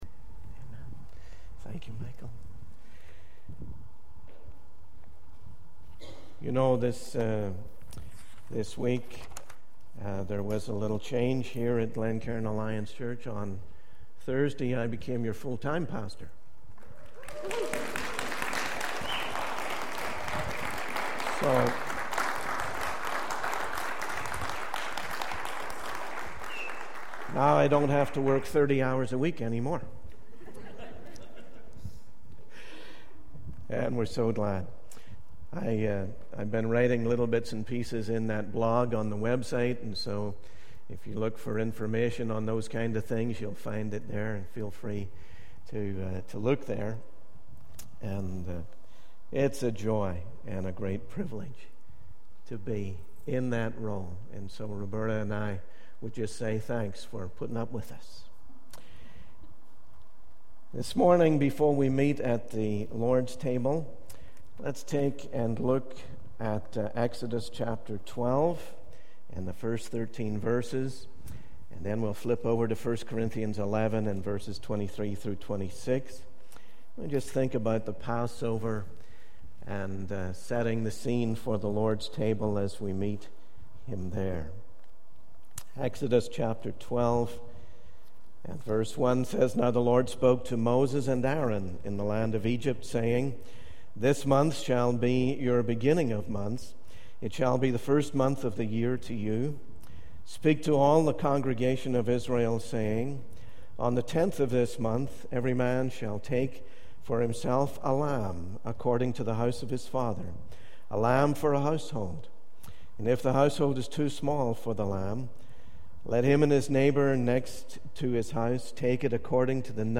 In this sermon, the speaker emphasizes the significance of the Passover and its connection to Jesus as the Lamb of God. The progression from a simple lamb to 'your lamb' highlights the personal experience of knowing Jesus as one's Savior.